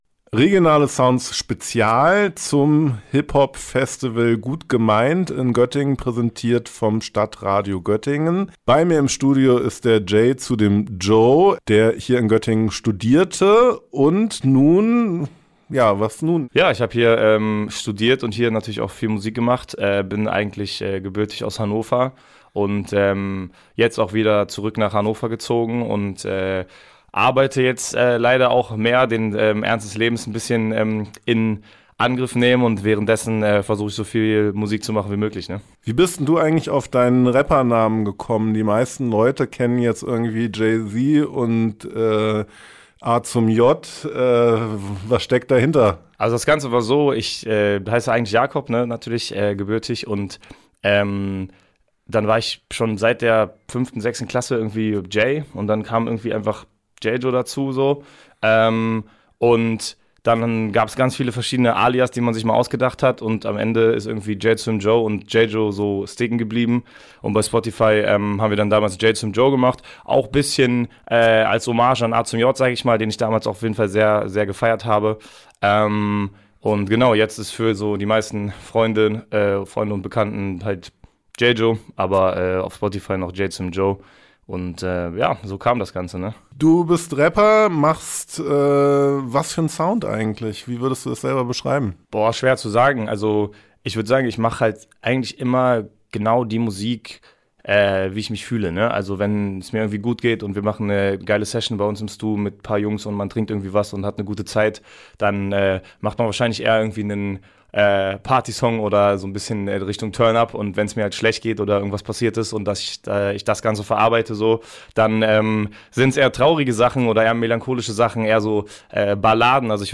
Interview mit Rapper